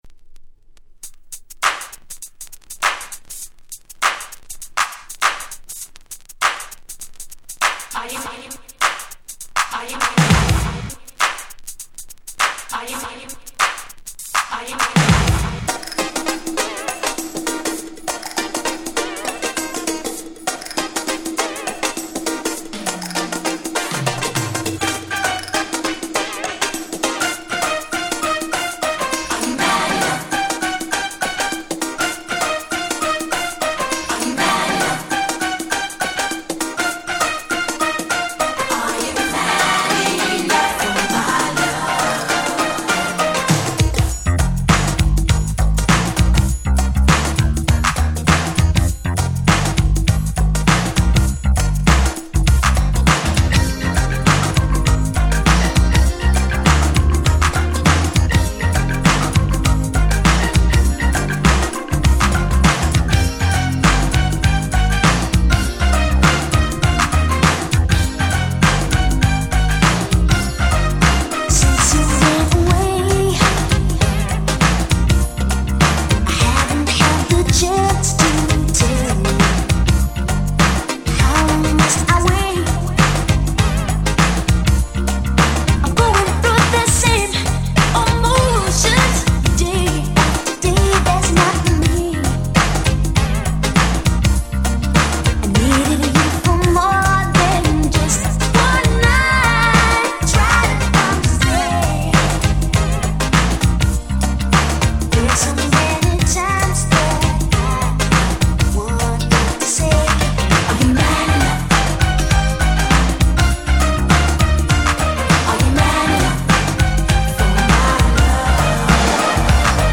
87' Nice Disco Boogie !!
爽やかでしなやか！